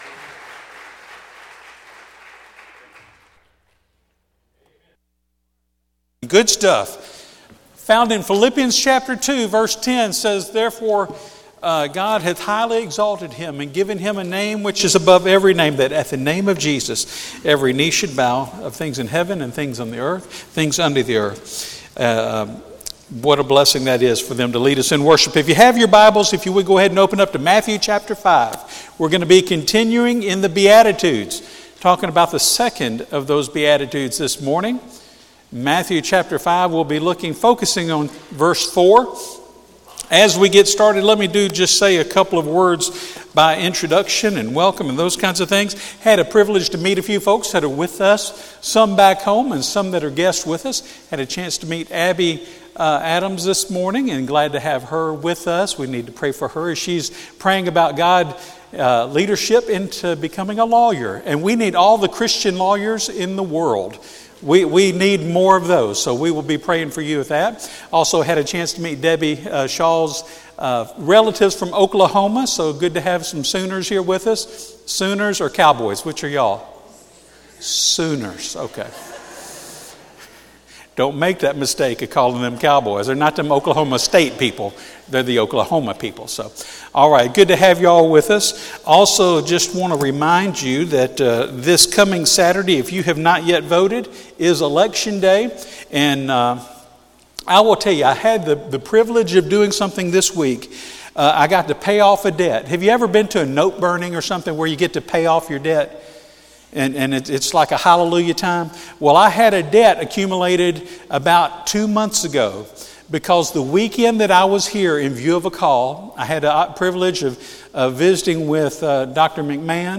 Morning Worship - Central Baptist Church